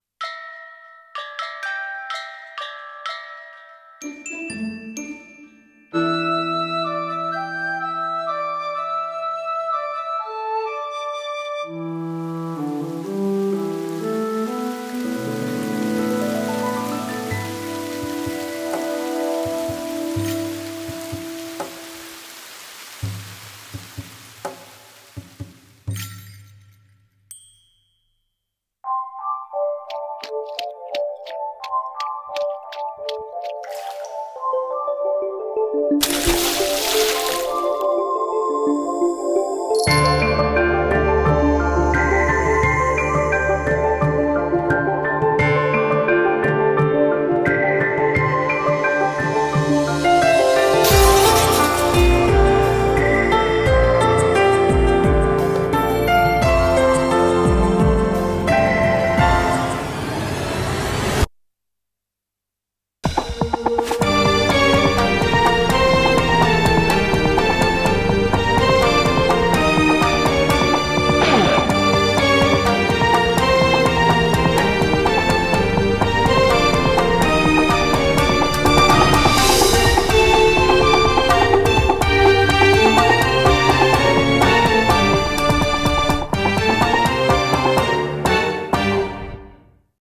CM風声劇「金魚の箱庭」